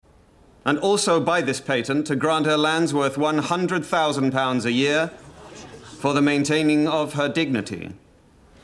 Cromwell pronounces her investiture so primly it’s like he inhales his own nostrils at the end.
cromwellspeaks.mp3